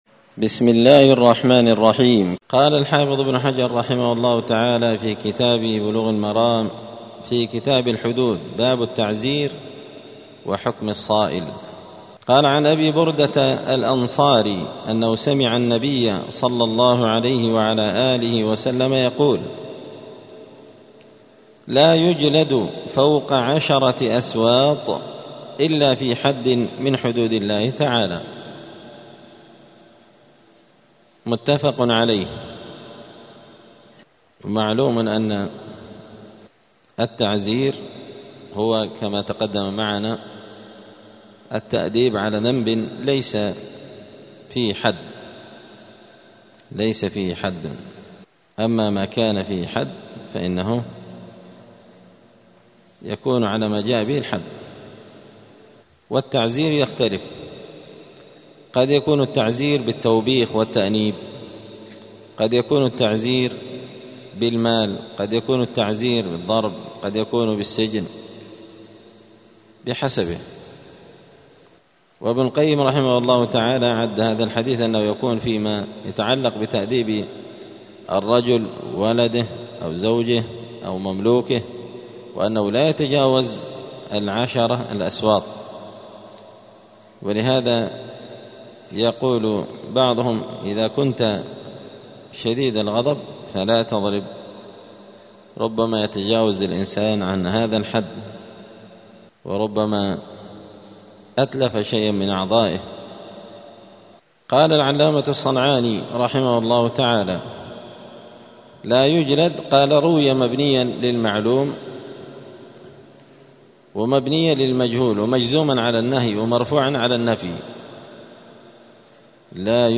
*الدرس الثامن والثلاثون (38) {باب التعزير إقالة ذوي العثرات ومن هم}*
دار الحديث السلفية بمسجد الفرقان قشن المهرة اليمن